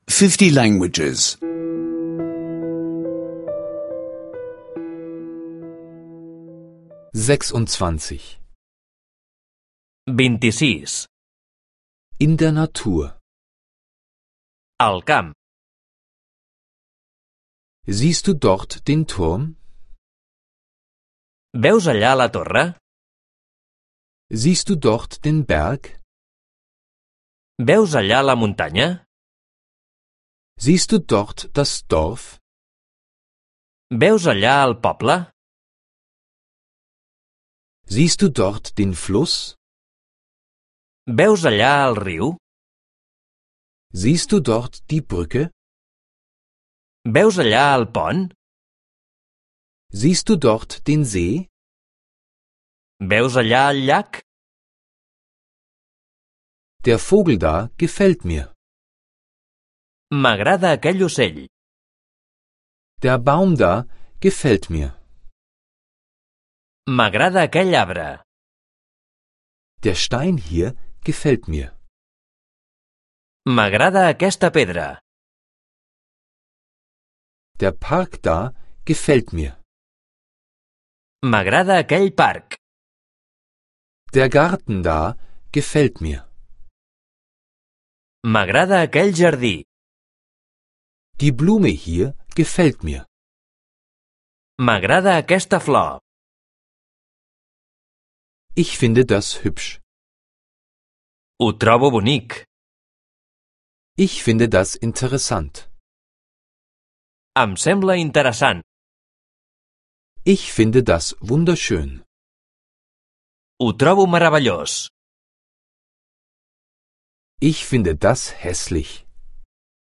Katalanisch Audio-Lektionen, die Sie kostenlos online anhören können.